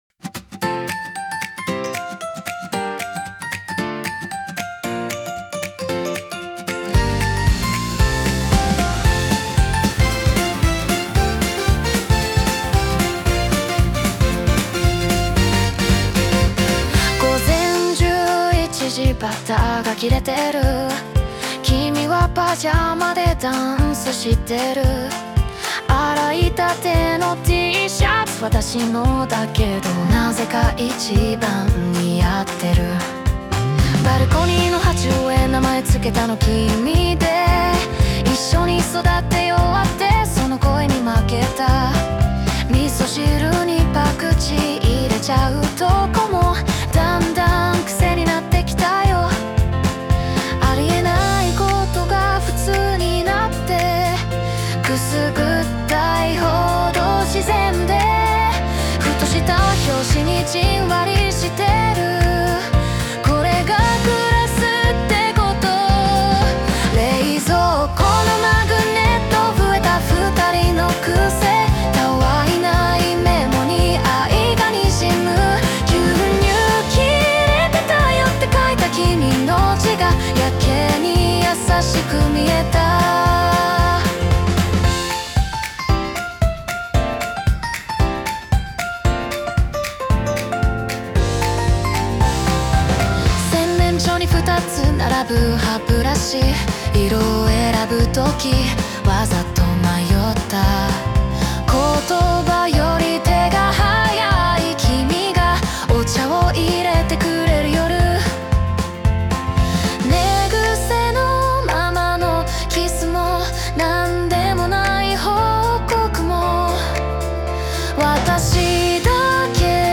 邦楽女性ボーカル著作権フリーBGM ボーカル
著作権フリーオリジナルBGMです。
女性ボーカル（邦楽・日本語）曲です。
この曲は、そんな“暮らしの中の愛”を、明るいサウンドに乗せたウェディングソング・新婚ソングです💛